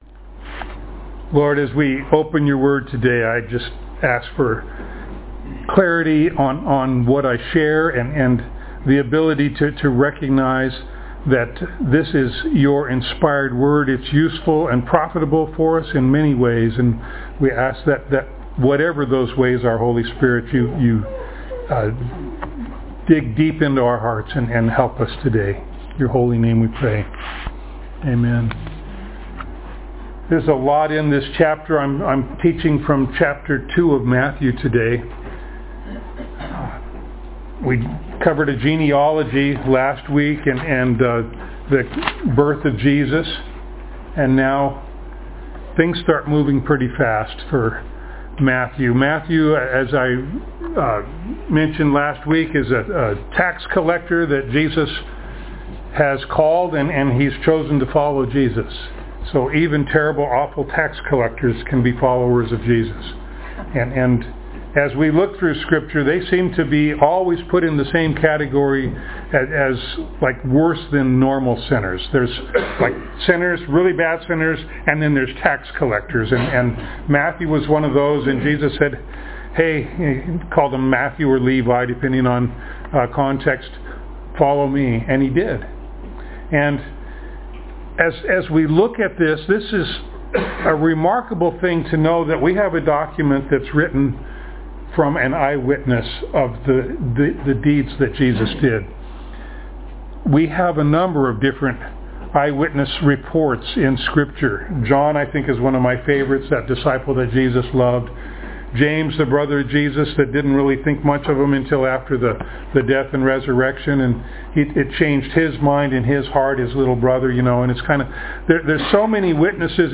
Passage: Matthew 2:1-23 Service Type: Sunday Morning